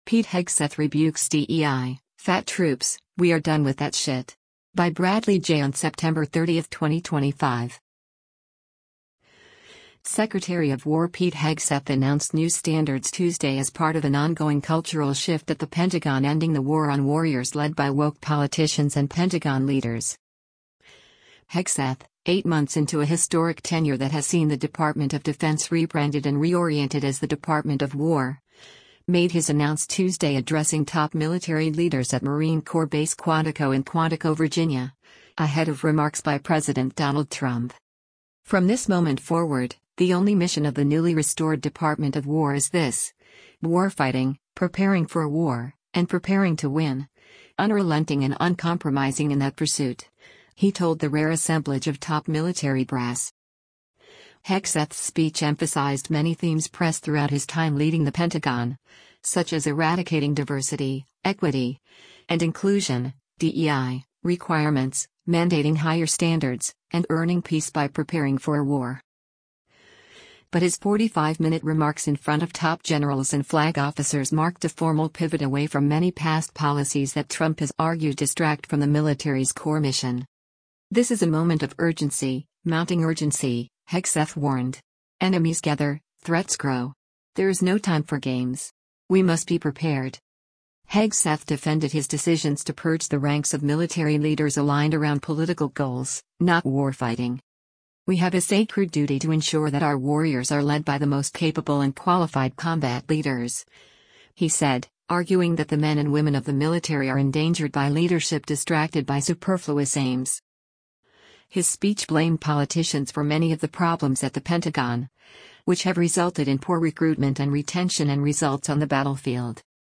Hegseth, eight months into a historic tenure that has seen the Department of Defense rebranded and reoriented as the Department of War, made his announced Tuesday addressing top military leaders at Marine Corps Base Quantico in Quantico, Virginia, ahead of remarks by President Donald Trump.
But his 45-minute remarks in front of top generals and flag officers marked a formal pivot away from many past policies that Trump has argued distract from the military’s core mission.